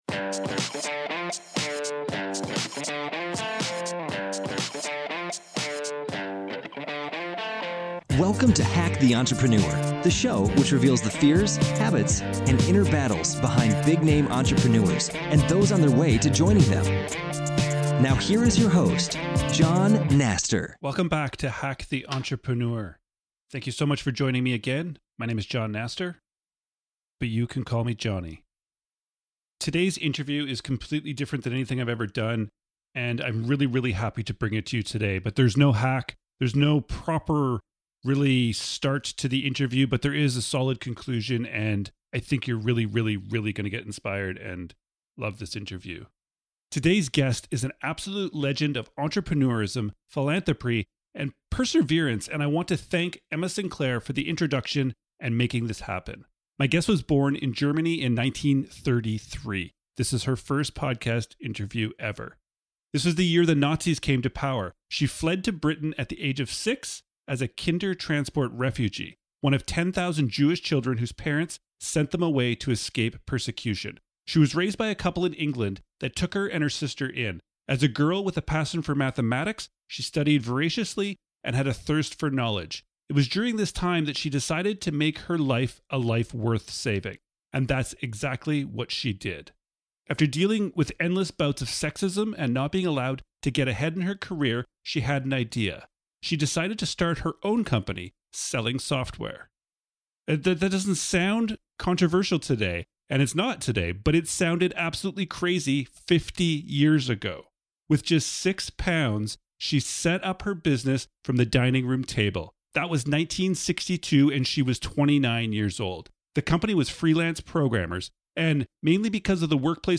Today’s guest is an absolute legend of entrepreneurism, philanthropy and perserverance.